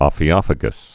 (ŏfē-ŏfə-gəs, ōfē-)